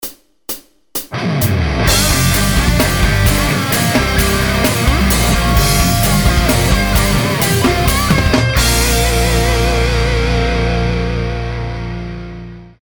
Toto aj s podkladom: